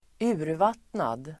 Ladda ner uttalet
urvattnad adjektiv (bildligt " kraftlös"), watered-down [figuratively " powerless" (wishy-washy)]Uttal: [²'u:rvat:nad] Böjningar: urvattnat, urvattnadeDefinition: som saknar smak